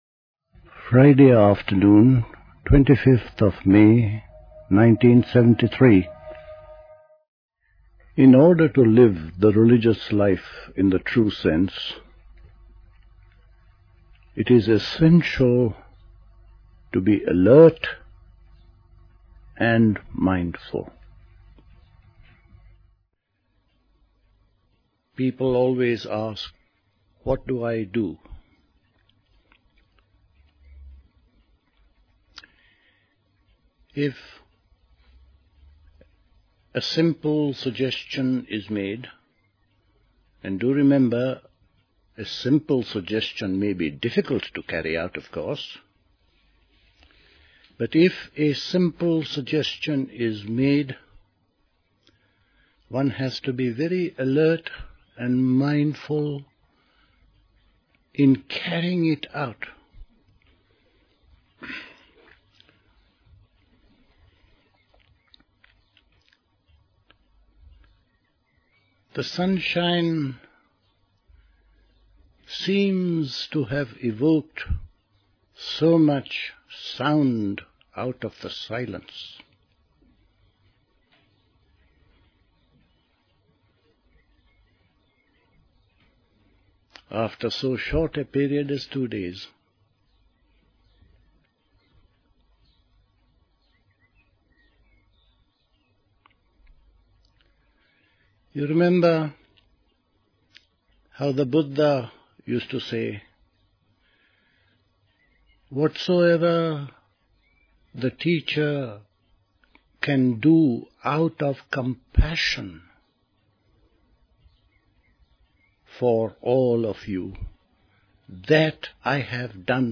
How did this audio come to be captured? Recorded at the 1973 Catherington House Summer School.